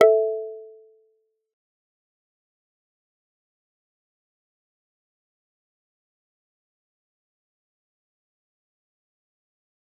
G_Kalimba-A4-f.wav